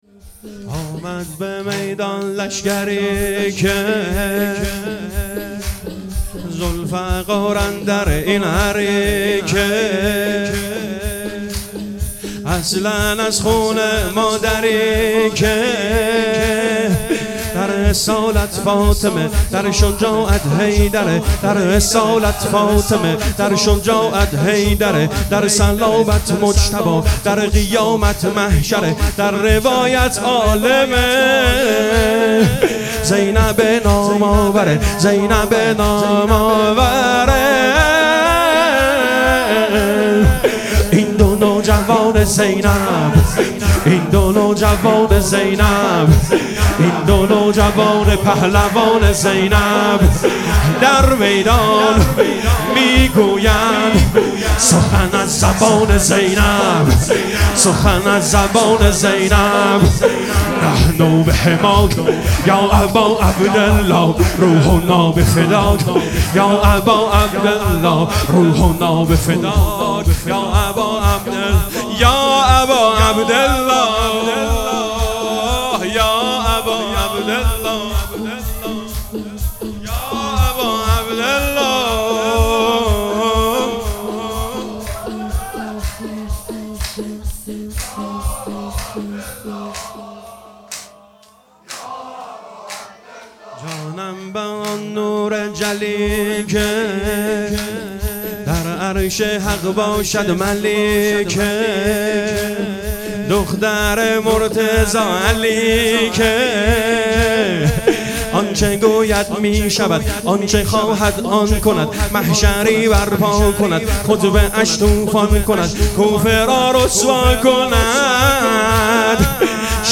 شب چهارم محرم الحرام 1446
شور